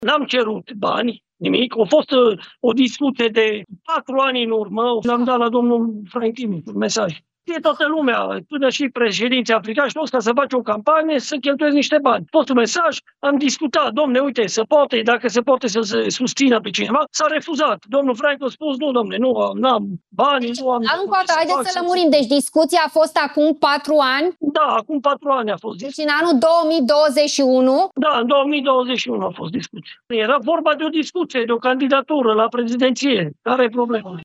Într-o emisiune la Realitatea Plus, Horațiu Potra a precizat că omul de afaceri a refuzat să îl sprijine pe Călin Georgescu.